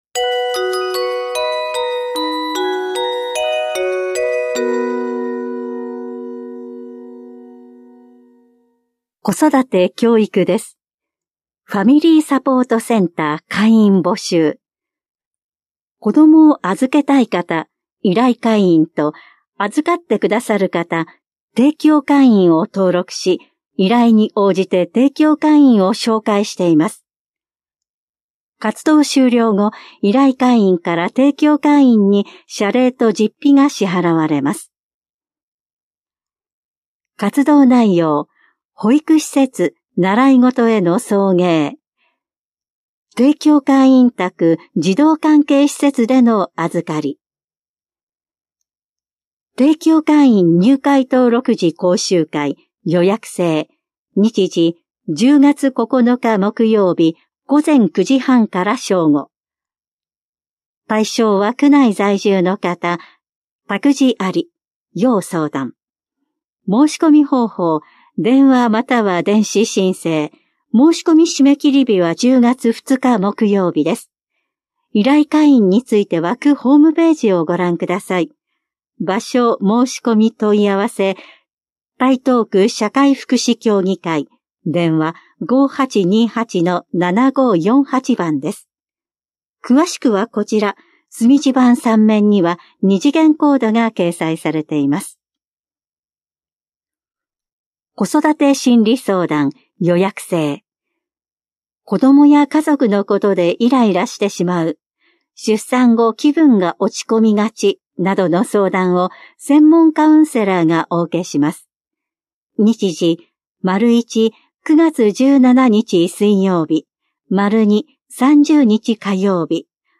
広報「たいとう」令和7年8月20日号の音声読み上げデータです。